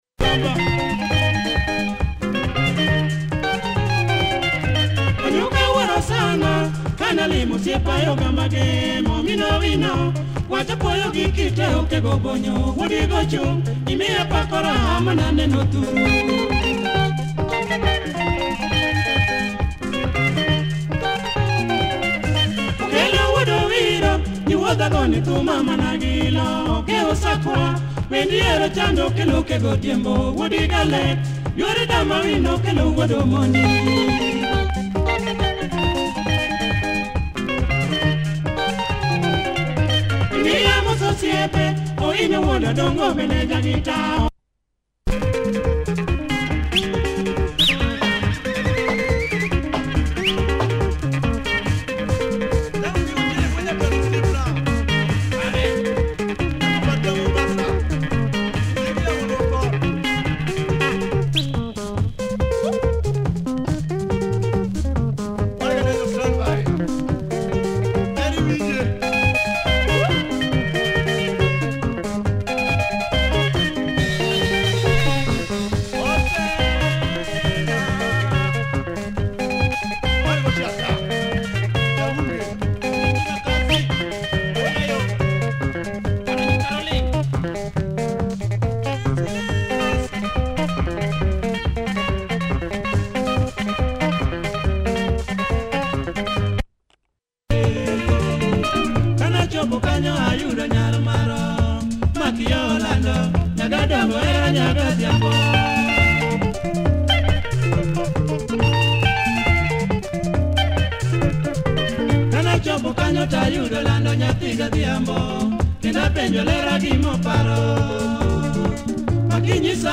Quality Luo benga, good beat, nice riff!